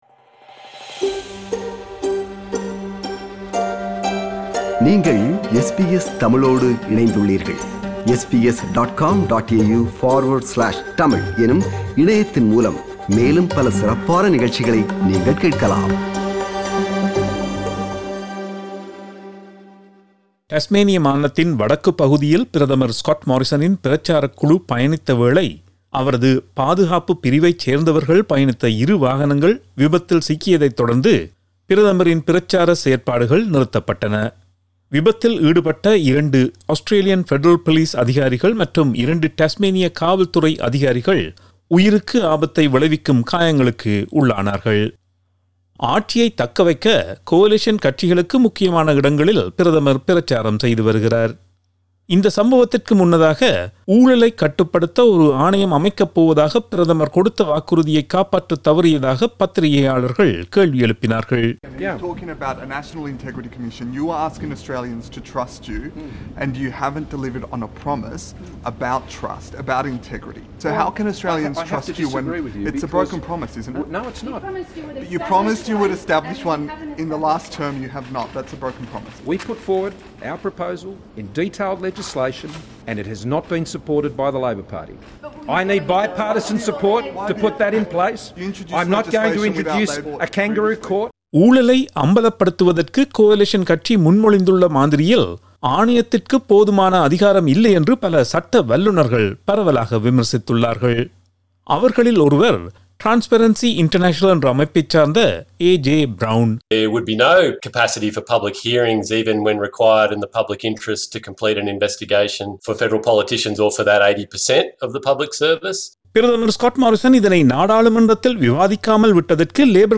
reports